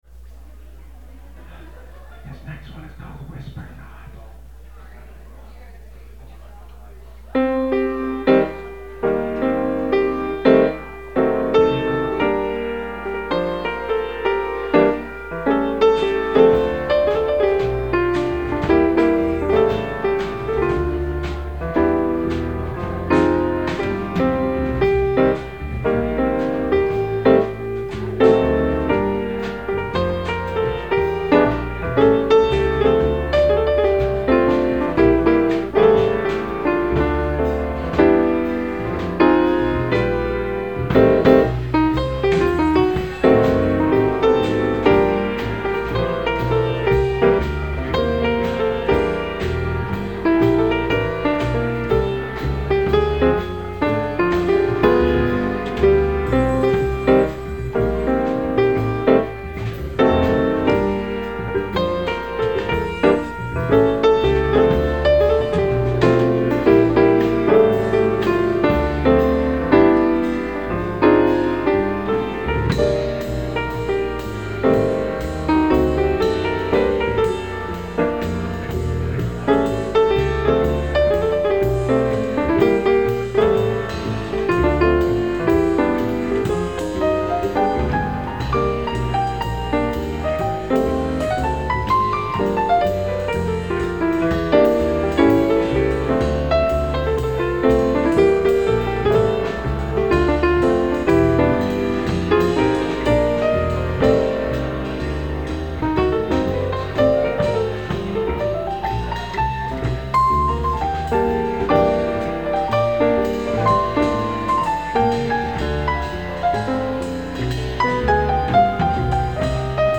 piano
bass
drums   Button Factory